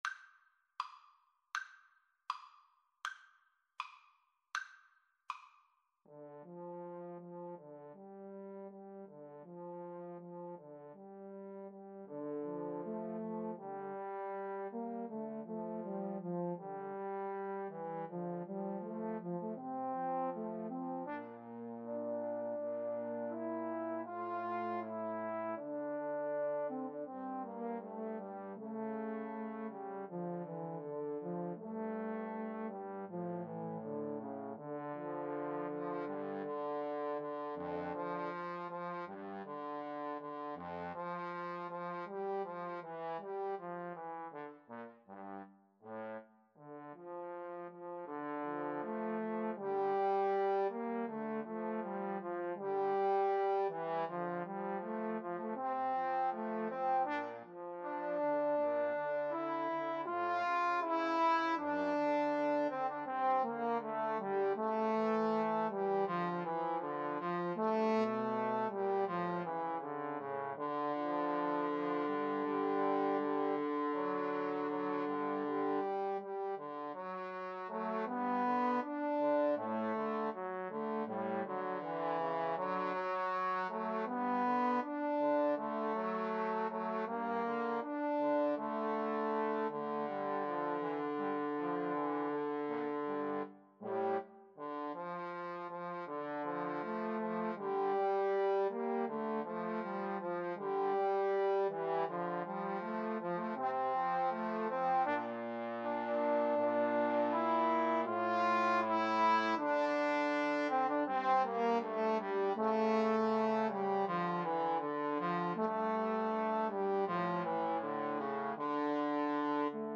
~ = 100 Andante